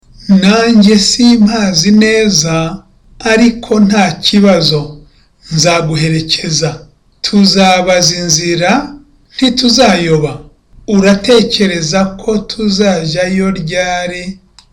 (Not sure)